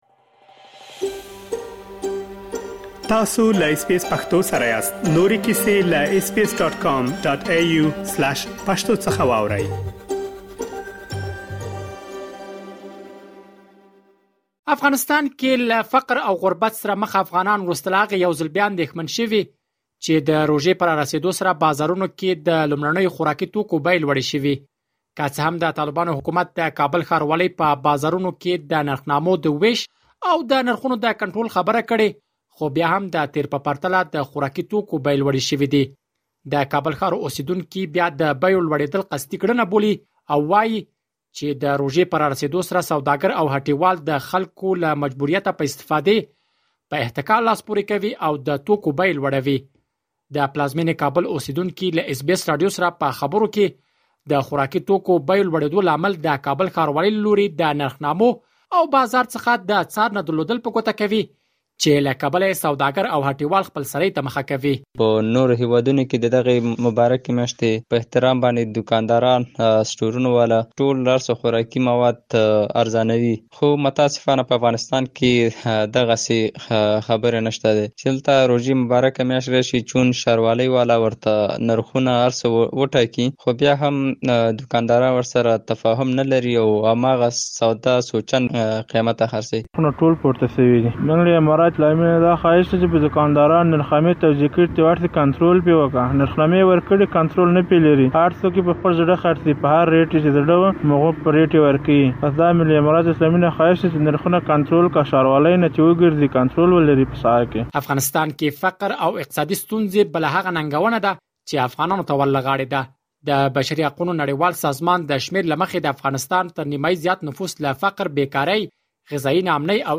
د پلازمینې کابل اوسېدونکو له اس بې اس راډیو سره په خبرو کې د خوراکي توکو بیو لوړېدو لامل د کابل ښاروالۍ له لوري د نرخنامو او بازار څخه د څار نه درلودل په ګوته کوي، چې له کبله یې سوداګر او هټیوال خپلسري ته مخه کوي. مهرباني وکړئ له ډېر معلومات په رپوټ کې واورئ.